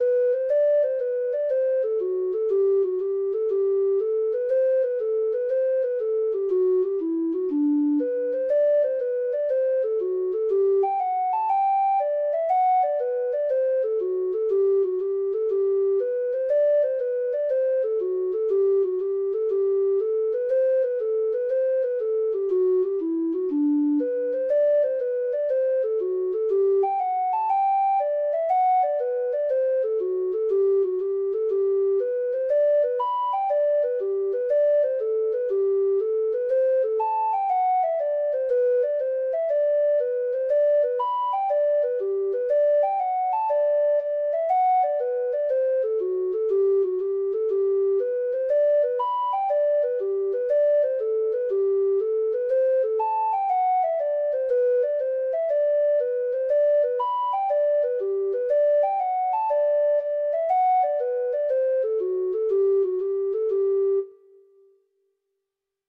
Treble Clef Instrument version
Traditional Music of unknown author.